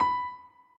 admin-amethyst-moon/b_piano2_v100l16-3o6b.ogg